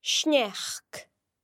The slender N sound is made by pressing the tongue against the palate, and is made when the N occurs next to e or i in a word.
You can also hear the slender N sound in sneachd (snow):